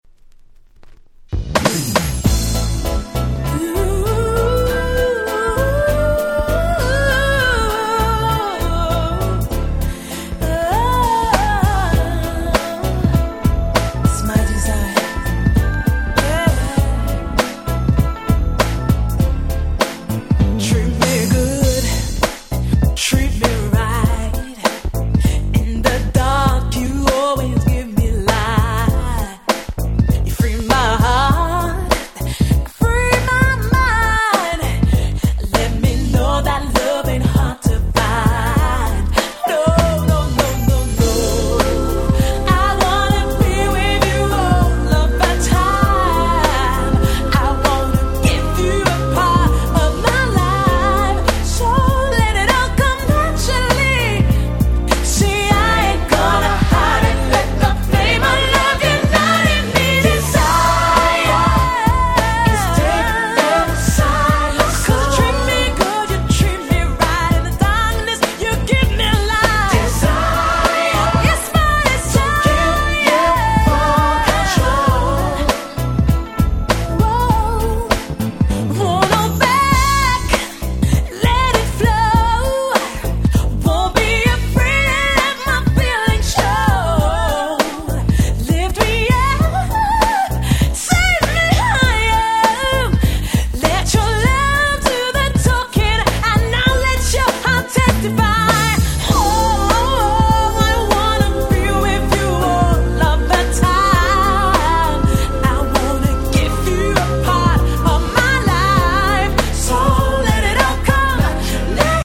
UK R&B Classic !!